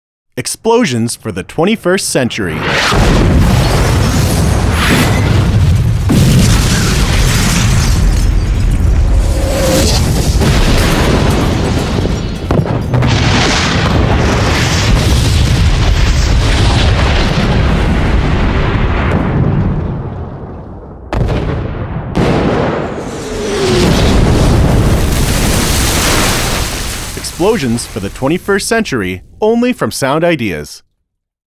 Take an abandoned iron ore mine the size of a football stadium. Add one hundred and fifty pounds of explosives.
Using glass, boulders and earth, metal and wood, they have enhanced the original recordings to give you the same effects used in most action-feature films today.
• Digitally recorded in stereo
explosions for the 21st century with VO.mp3